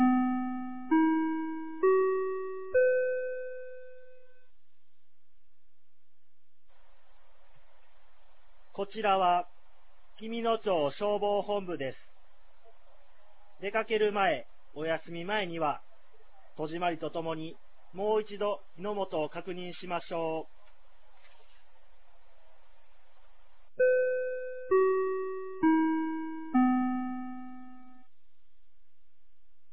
2023年09月09日 16時00分に、紀美野町より全地区へ放送がありました。